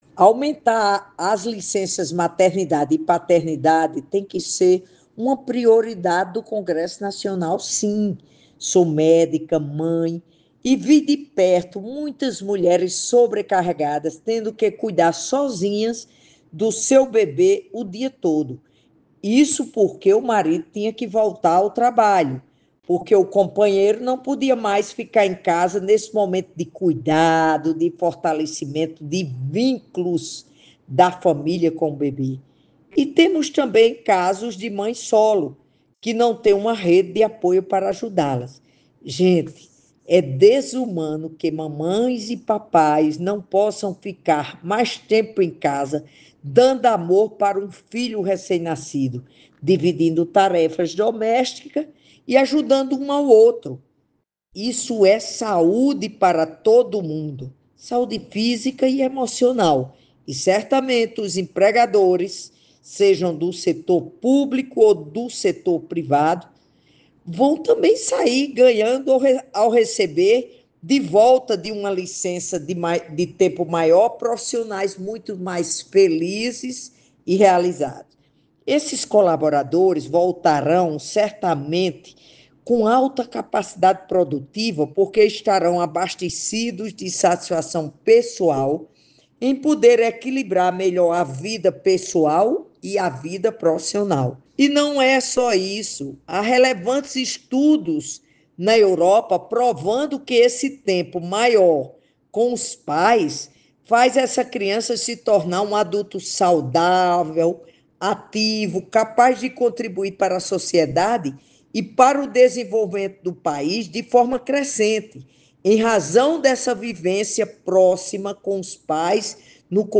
Ouça áudio de Zenaide sobre o tema: